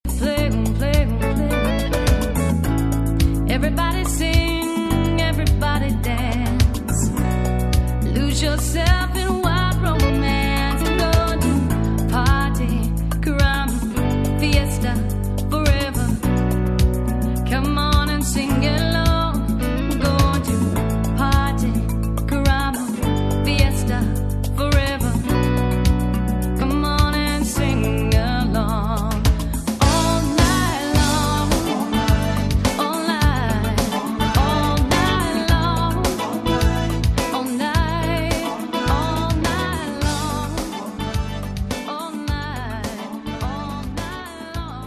With Both Male and Female Vocals and Harmonies through out.